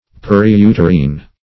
\Per`i*u"ter*ine\